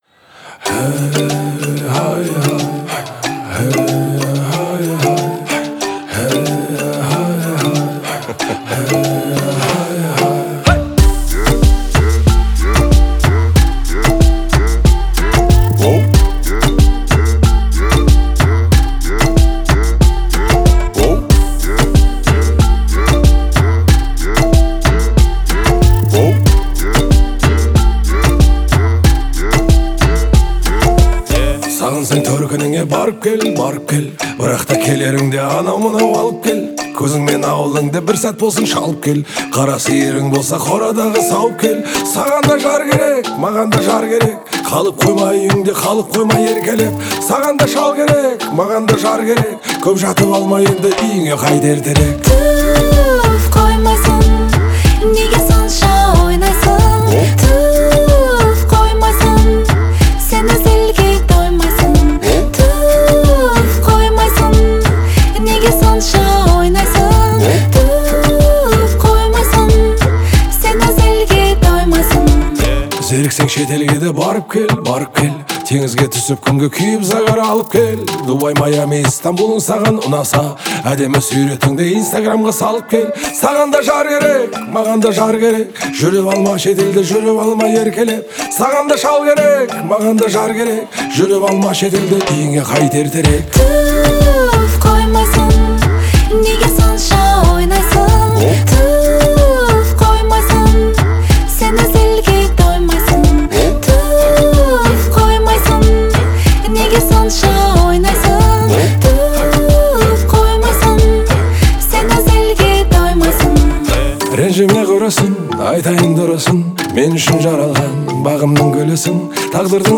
сочетает в себе элементы хип-хопа и этнической музыки